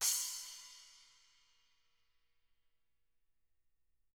Index of /90_sSampleCDs/ILIO - Double Platinum Drums 1/CD4/Partition H/SPLASH CYMSD